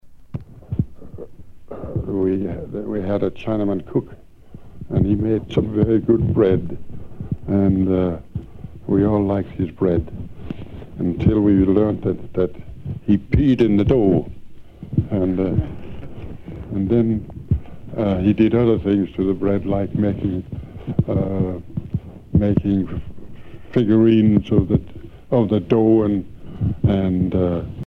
Témoignages et chansons maritimes
Catégorie Témoignage